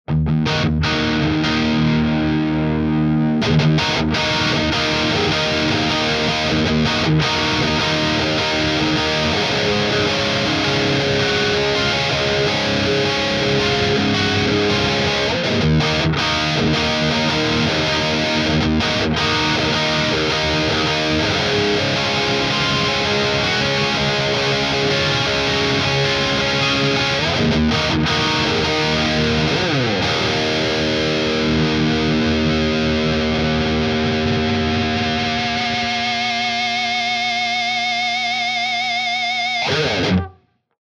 The classic germanium treble booster.
Boosted Rhythm (0:40)
tb-eminor-boost.mp3